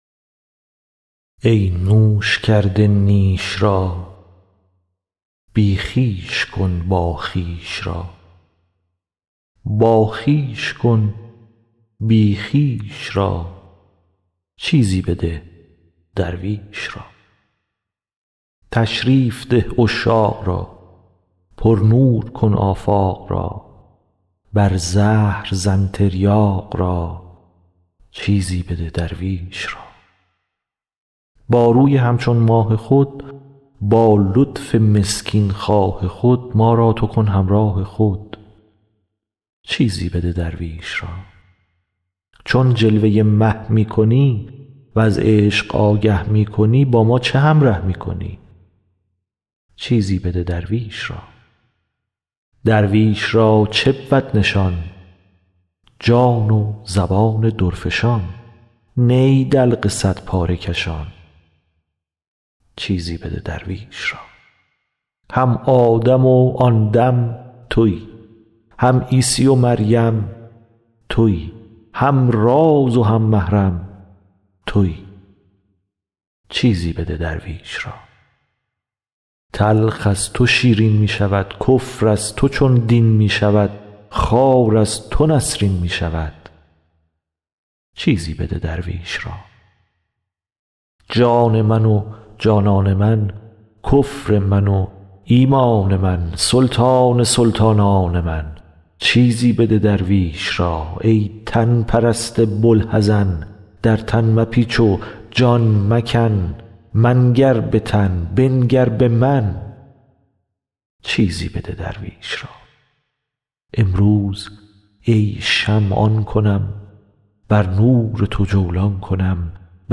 مولانا دیوان شمس » غزلیات غزل شمارهٔ ۱۵ به خوانش